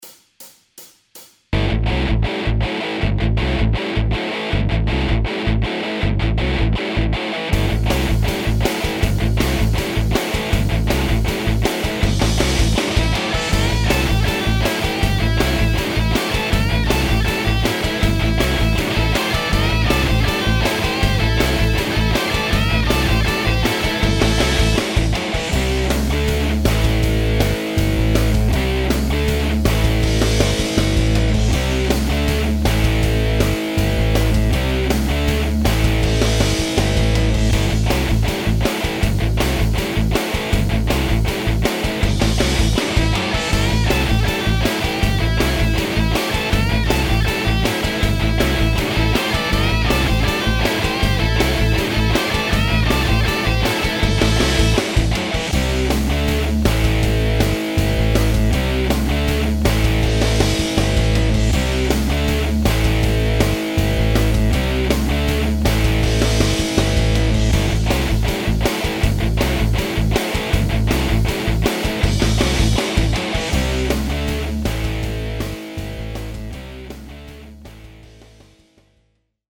Такой вот вариант с басом.